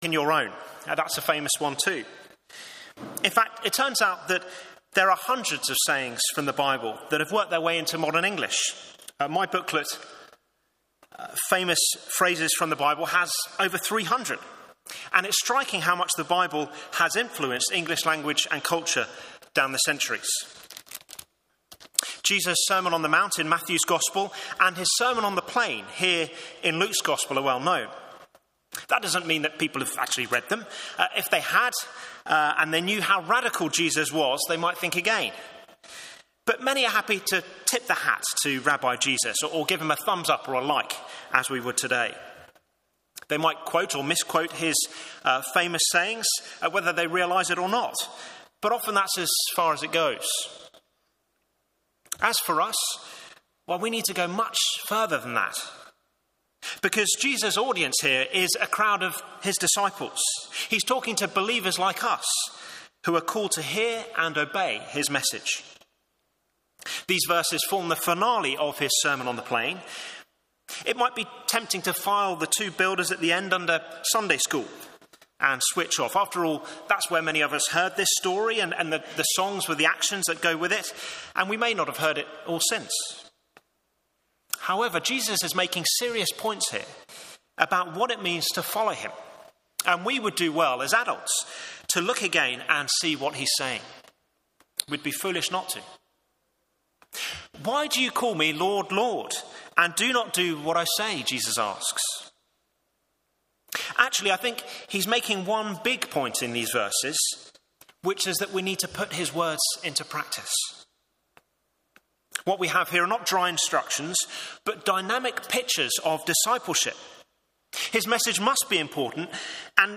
Media for Morning Service on Sun 17th Nov 2024 10:30 Speaker: Passage: James 1:19-27, Luke 6:39-49 Series: Theme: Sermon In the search box please enter the sermon you are looking for.